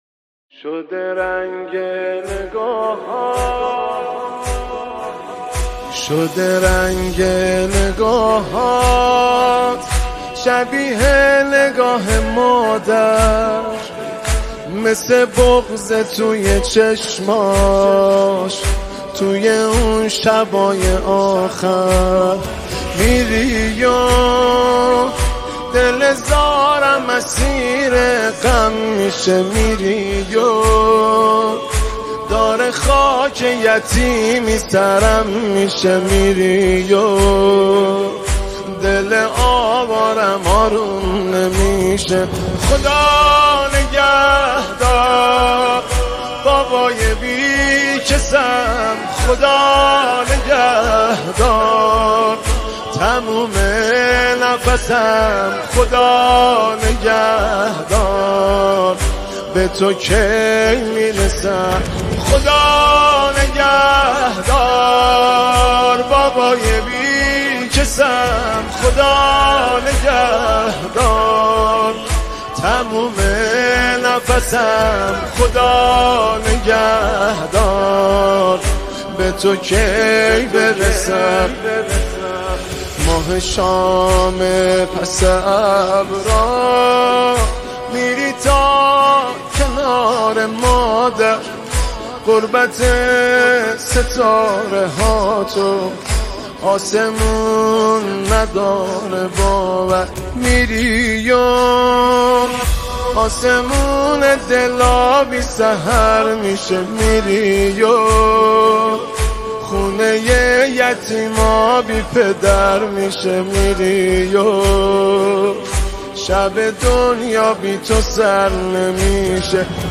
نماهنگ حزین
با نوای دلنشین